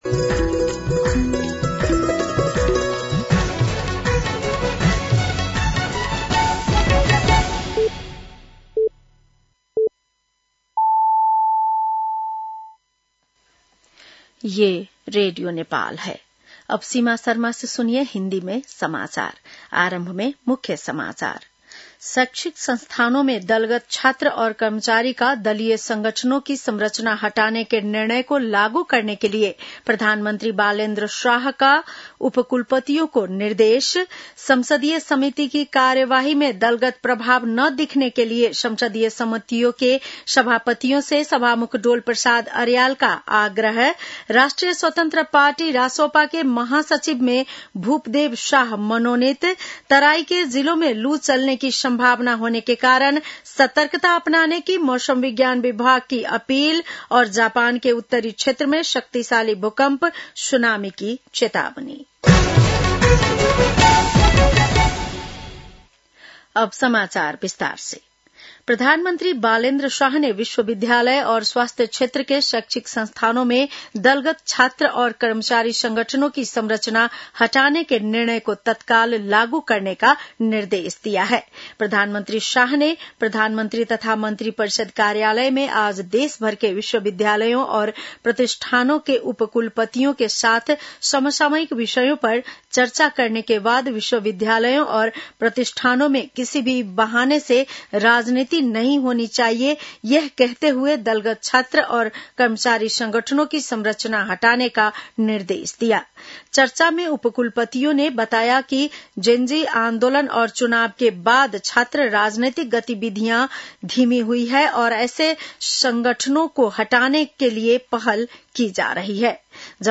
बेलुकी १० बजेको हिन्दी समाचार : ७ वैशाख , २०८३